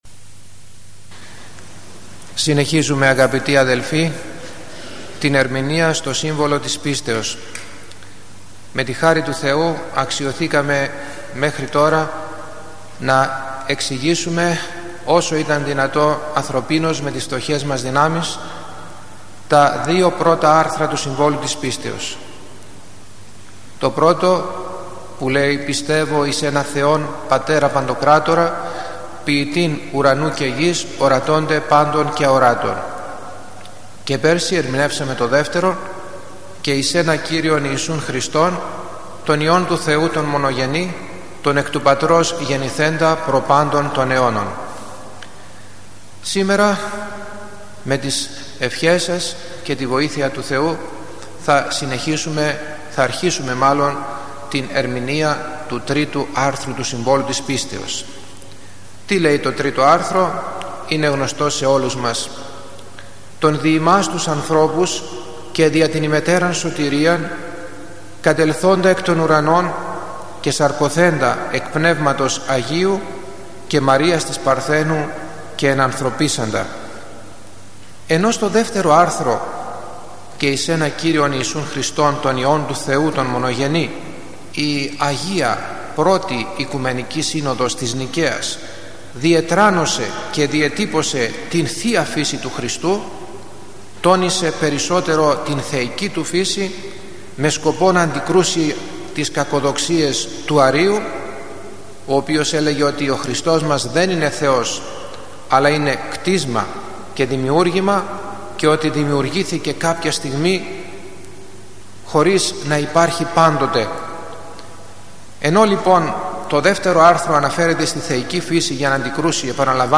Περί της θείας ενανθρωπήσεως (Α’) – Ομιλία στο Σύμβολο της Πίστεως